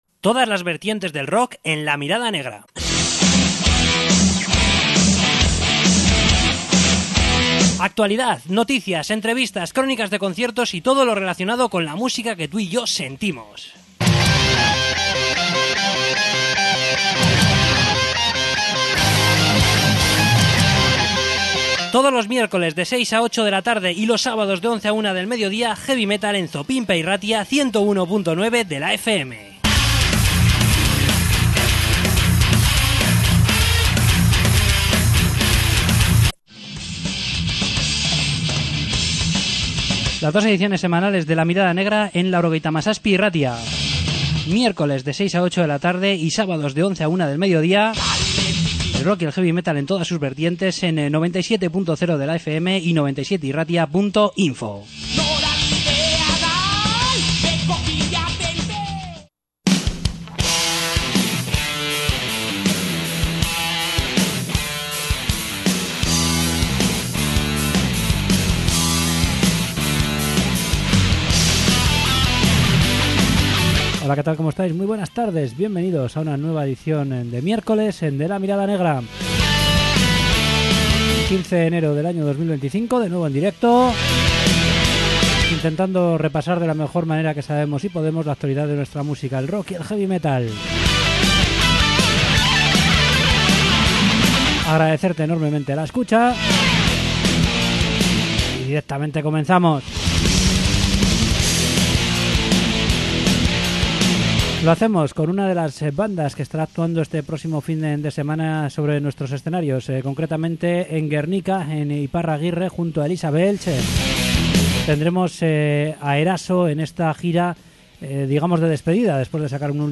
Entrevista con Eraso!
Entrevista con Nurcry